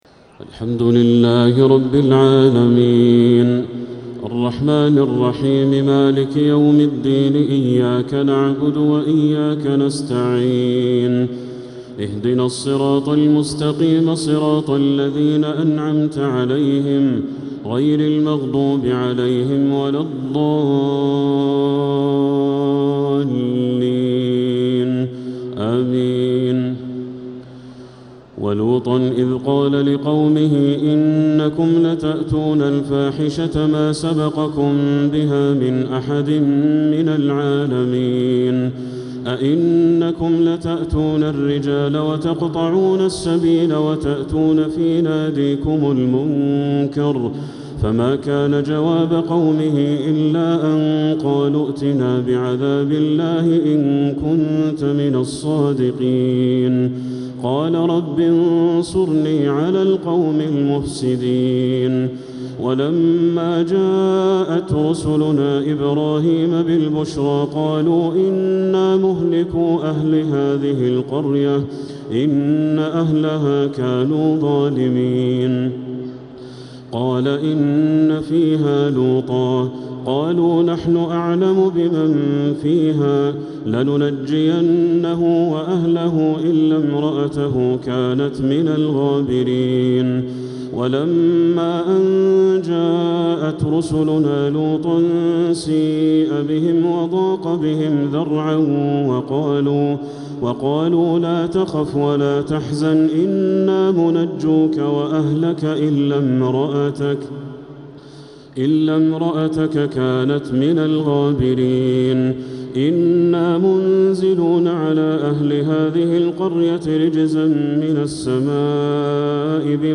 تهجد ليلة 23 رمضان 1447هـ من سورتي العنكبوت (28-69) و الروم (1-32) | Tahajjud 23rd night Ramadan 1447H Surah Al-Ankaboot and Ar-Room > تراويح الحرم المكي عام 1447 🕋 > التراويح - تلاوات الحرمين